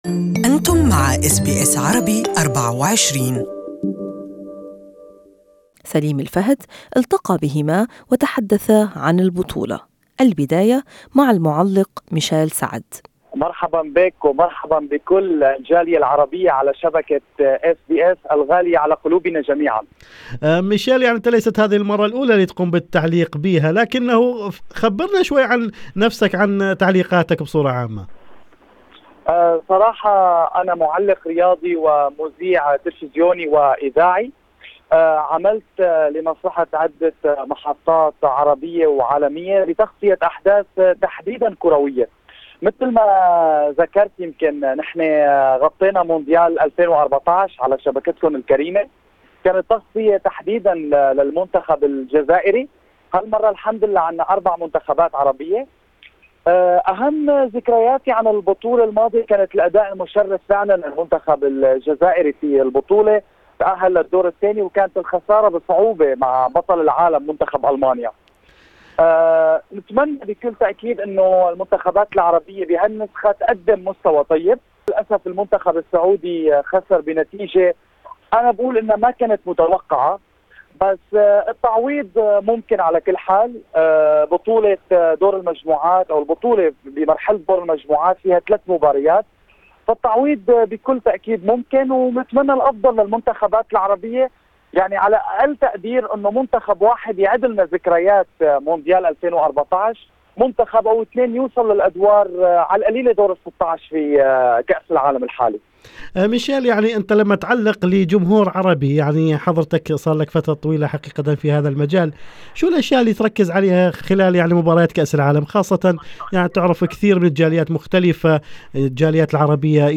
المزيد في اللقاء الصوتي أعلاه.